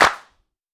Clap
Original creative-commons licensed sounds for DJ's and music producers, recorded with high quality studio microphones.
00s Clap Single Shot G Key 05.wav
hand-clap-g-key-04-QuK.wav